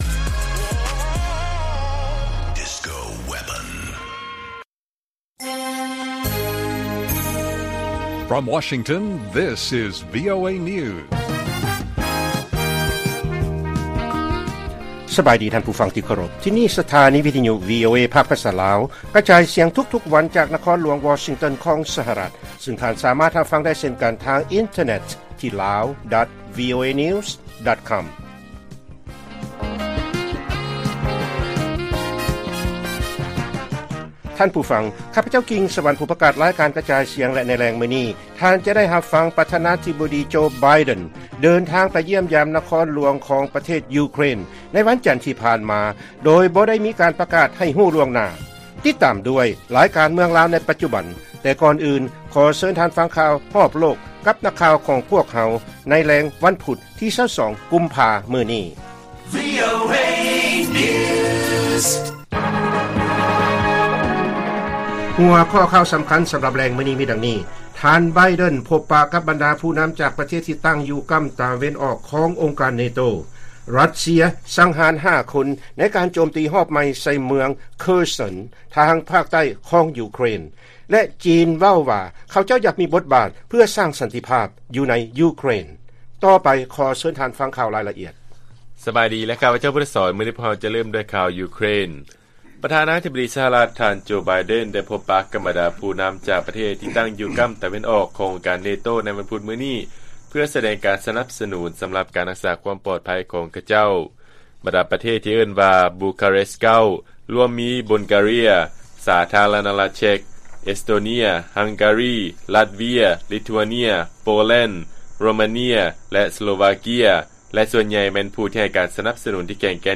ລາຍການກະຈາຍສຽງຂອງວີໂອເອ ລາວ: ທ່ານ ໄບເດັນ ພົບປະກັບບັນດາຜູ້ນຳ ຈາກ ປະເທດທີ່ຕັ້ງຢູ່ກໍ້າຕາເວັນອອກຂອງອົງການ NATO